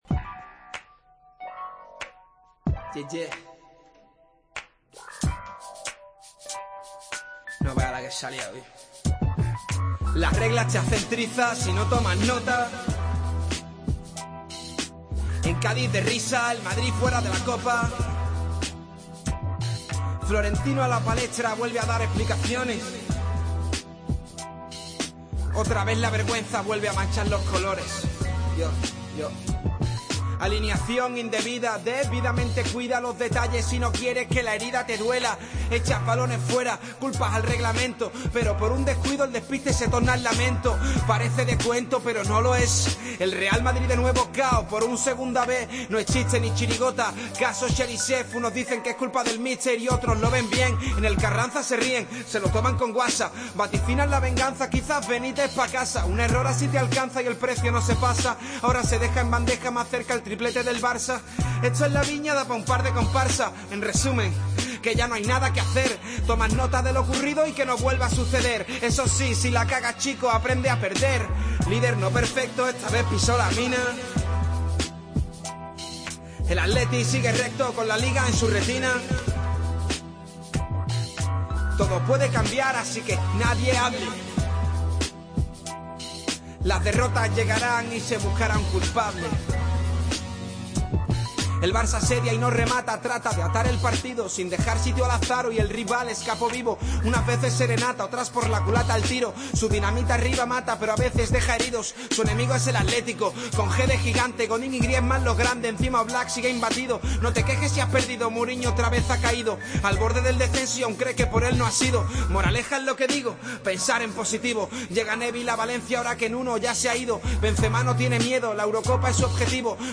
Tiempo de Juego a ritmo de rap